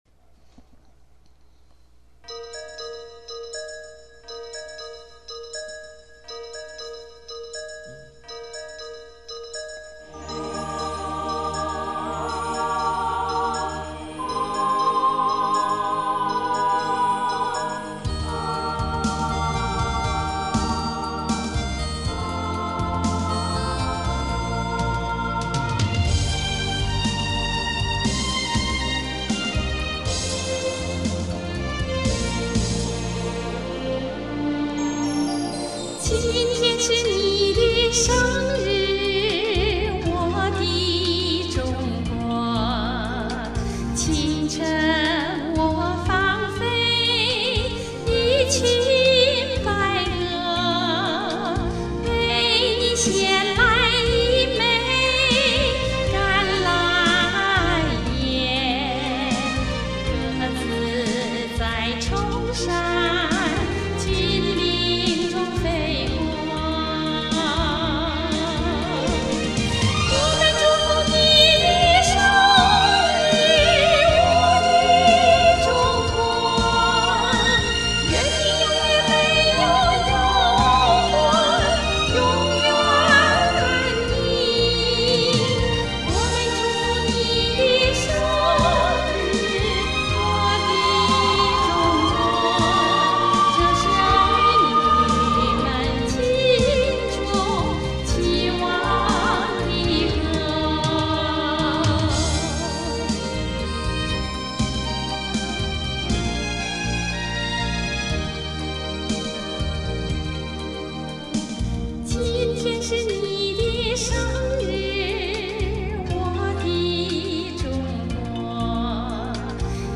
(网友翻唱)